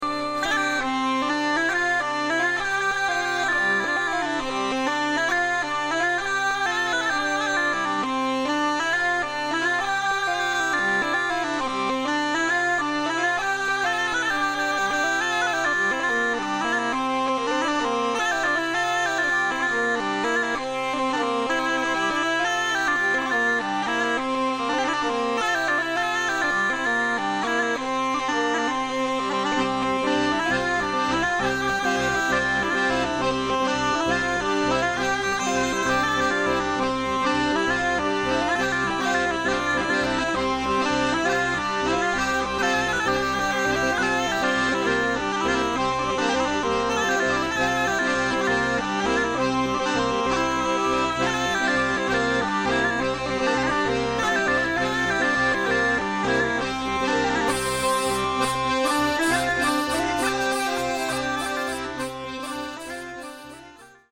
3 Time Bourree